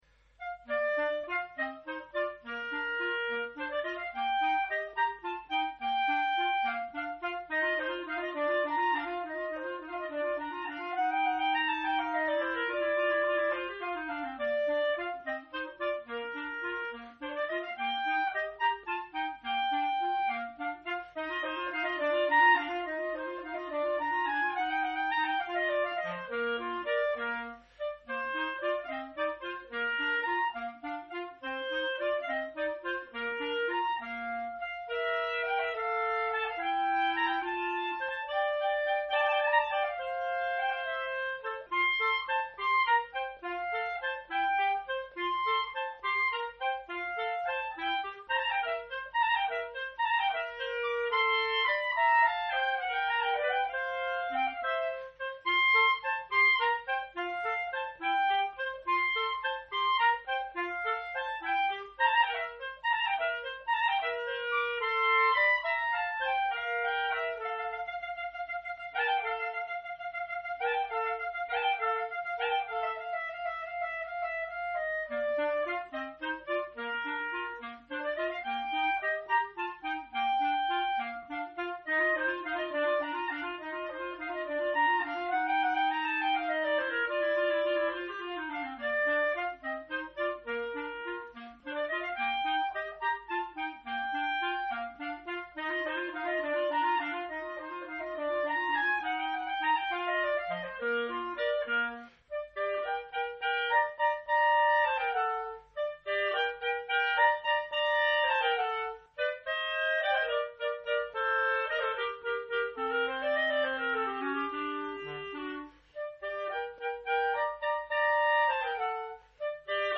Duo N° 1 opus 5 en ut M.
2e mouvement, Rondo Pastorella (2'56)